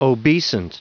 Prononciation du mot obeisant en anglais (fichier audio)
Prononciation du mot : obeisant
obeisant.wav